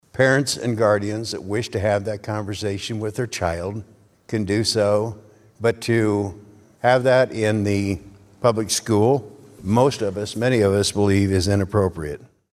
Senator Ken Rozenboom, a Republican from Oskaloosa, says those topics are totally unnecessary for elementary students.